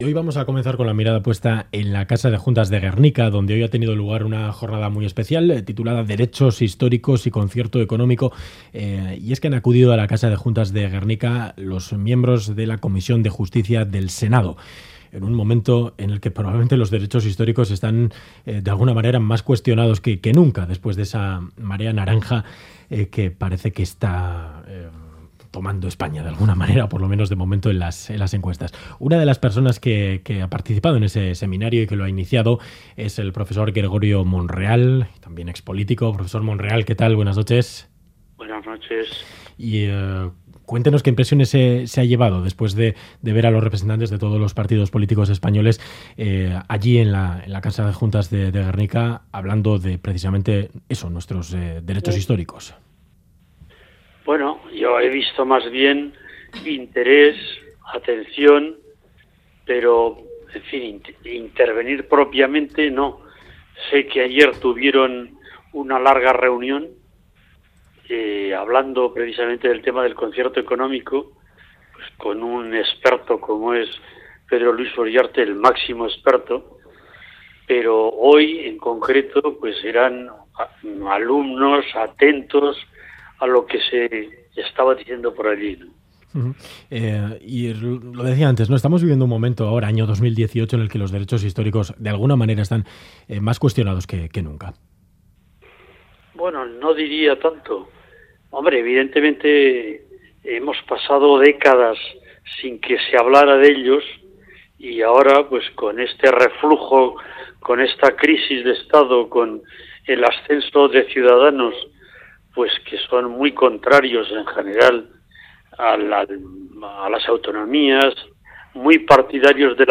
Audio: Entrevista al profesor de historia del derecho y senador por Gipuzkoa del Frente Autonómico en la legislatura constituyente Gregorio Monreal.